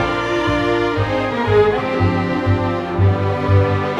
Rock-Pop 11 Strings 04.wav